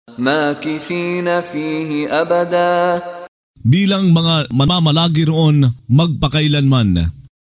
Pagbabasa ng audio sa Filipino (Tagalog) ng mga kahulugan ng Surah Al-Kahf ( Ang Yungib ) na hinati sa mga taludtod, na sinasabayan ng pagbigkas ng reciter na si Mishari bin Rashid Al-Afasy.